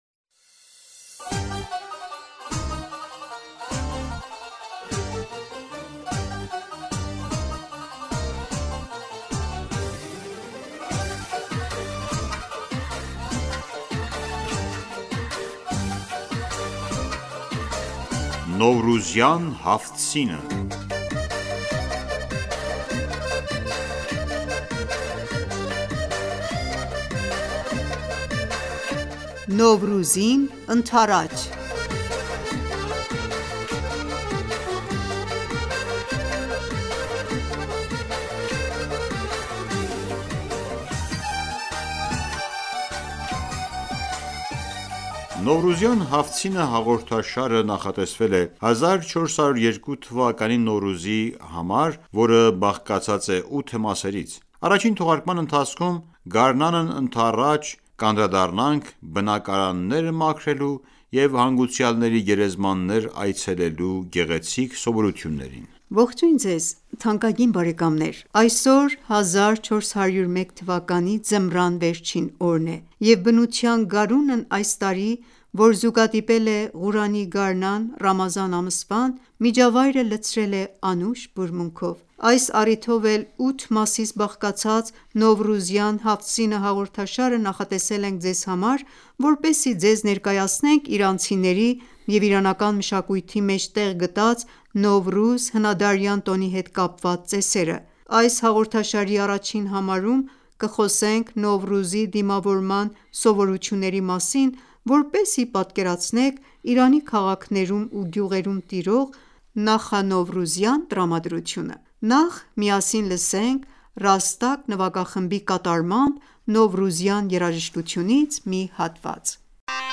«Նովռուզյան Հաֆթ սինը» հատուկ հաղորդումը նախատեսվել է 1402թ. Նովռուզի համար, որը բաղկացած է 8 մասերից: Առաջին թողարկման ընթացքում գարնանն ընդառաջ, կանդրադ...